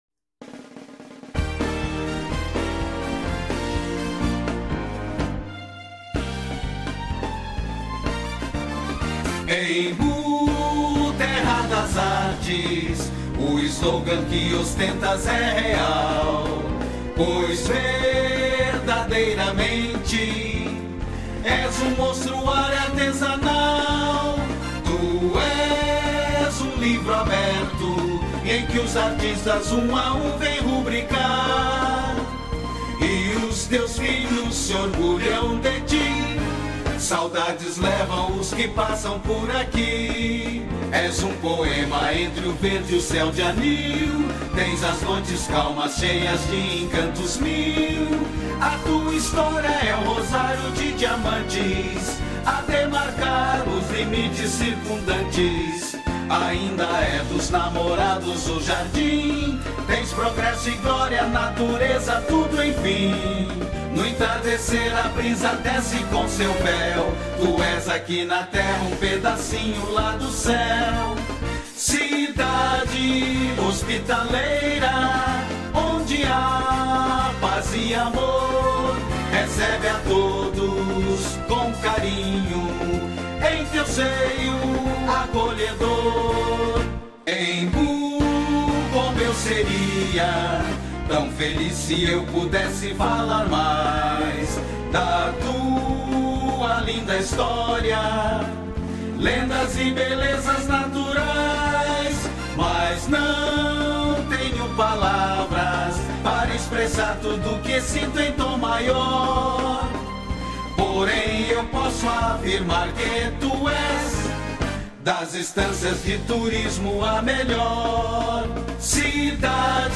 Cantado